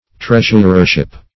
Search Result for " treasurership" : Wordnet 3.0 NOUN (1) 1. the position of treasurer ; The Collaborative International Dictionary of English v.0.48: Treasurership \Treas"ur*er*ship\, n. The office of treasurer.